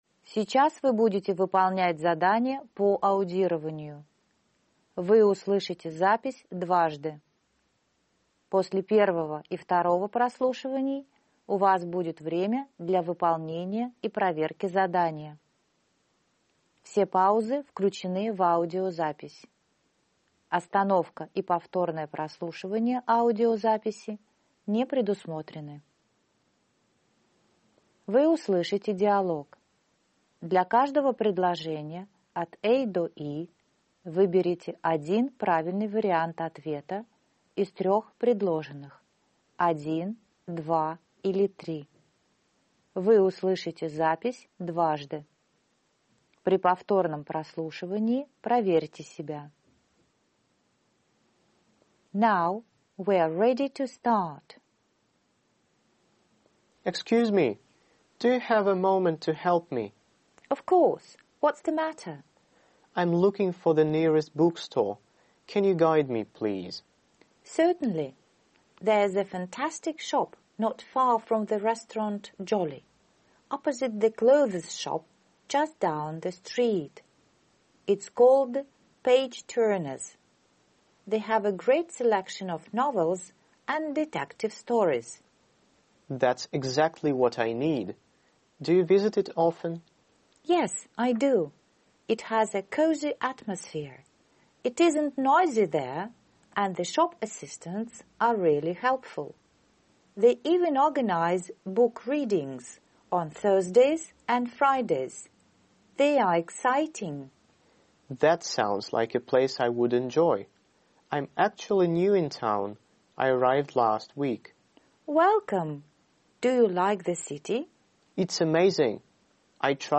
Вы услышите диалог.